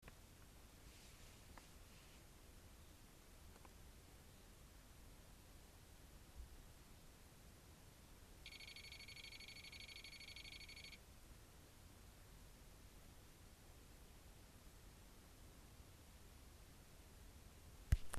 Piepston beim Ducato X250
Als ich vor einer Woche die Starterbatterie an meinem DucatoX250 anschloss erklang ein Piepston von 2 Sekunden der sich immer alle 18 Sekunden wiederholte. Das Piepsen das an ein Modem erinnert das eine Telefonnummer anwählt kommt irgend wo hinter dem Sicherungskasten links unter dem Lenkrad.
Das Piepsen kommt nach +- 10 Sekunden
ducatopieps.mp3